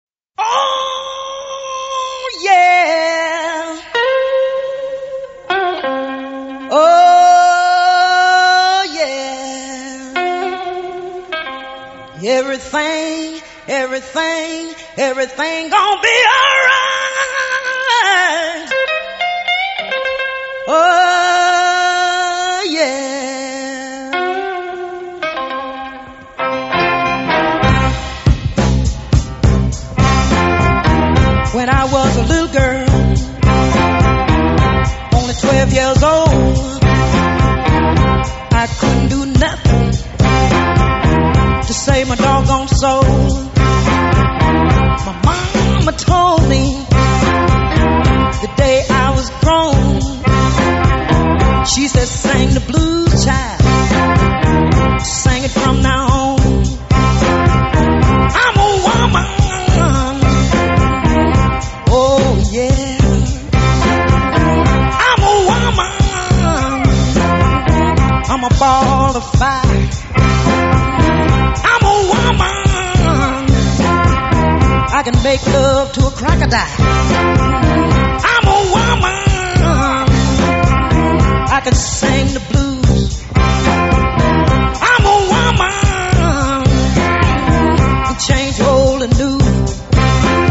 藍調音樂